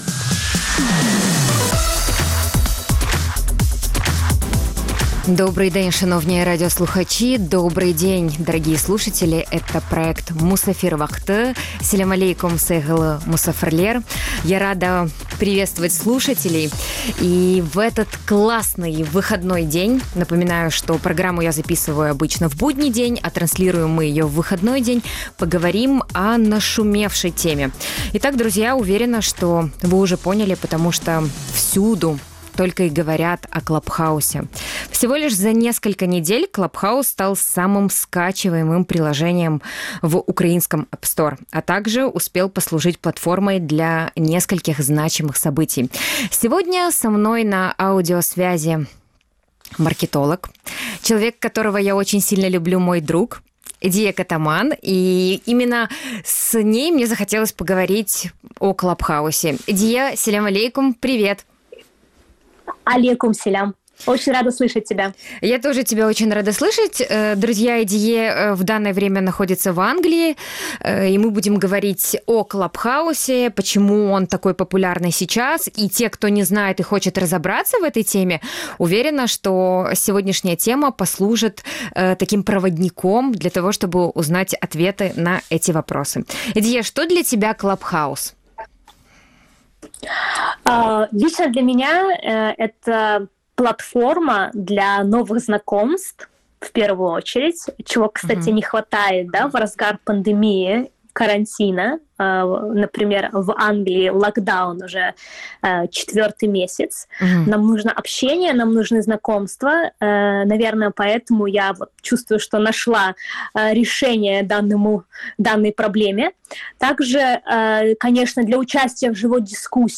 В сегодняшнем выпуске говорим об этом явлении и о крымскотатарских «комнаты» в этом приложении. Гость эфира маркетолог